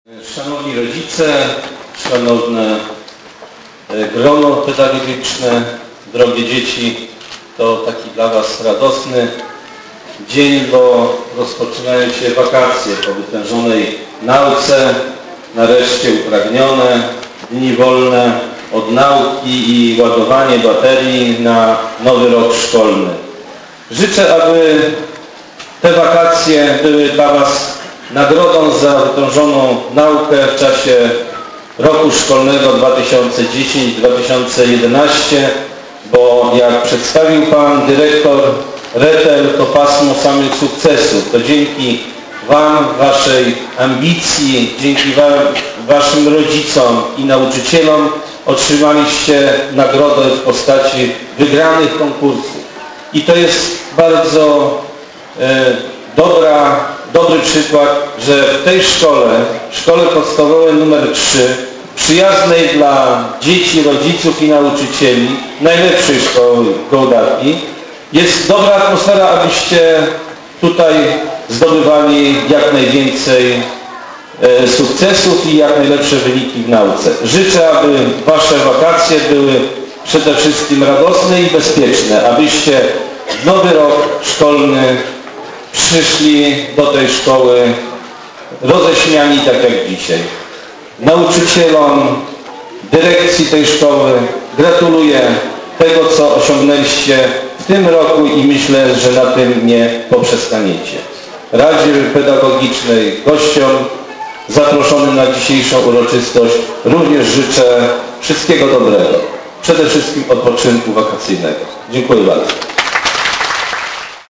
Taką sentencją chwilę po godzinie 9 rozpoczęło się zakończenie roku szkolnego 2010/2011 w Szkole Podstawowej nr 3 im. Tadeusza Kościuszki w Gołdapi.
Życzenia wojewody Mariana Podziewskiego